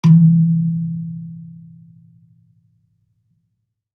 kalimba_bass-E2-ff.wav